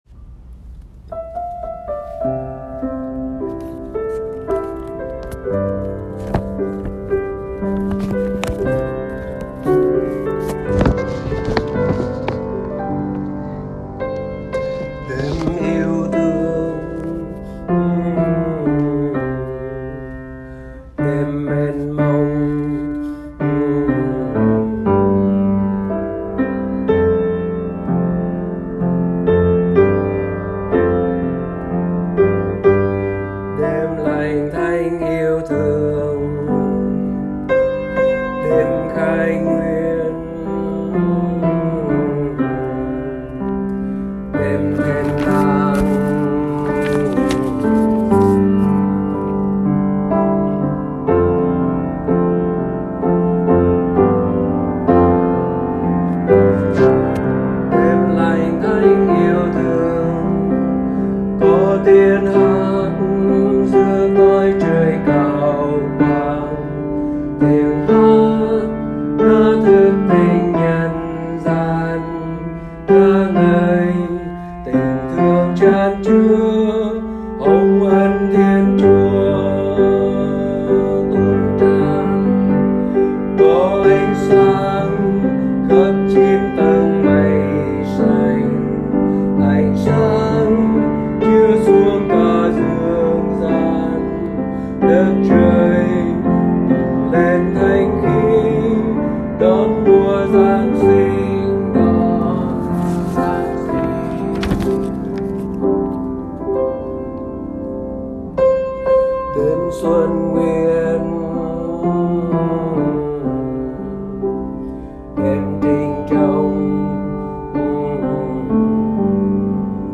Bè Nam
DemYeuThuong_BeNam.m4a